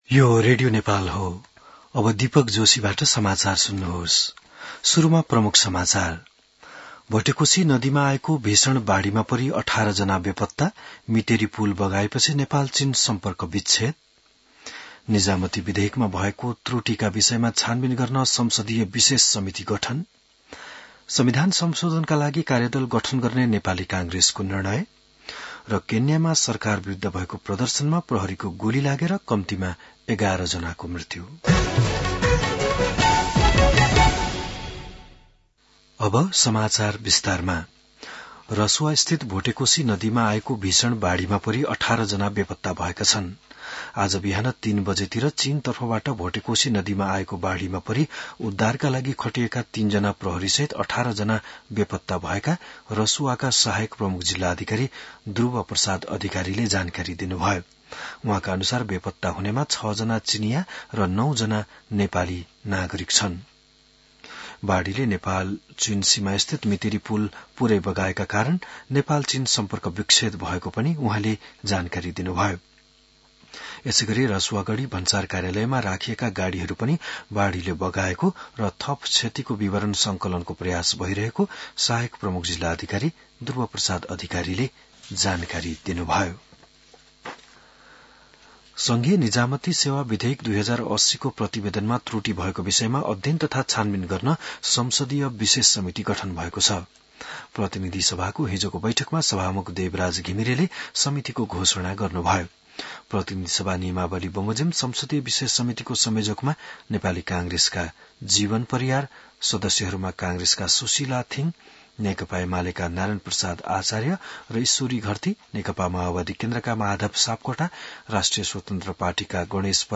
बिहान ९ बजेको नेपाली समाचार : २४ असार , २०८२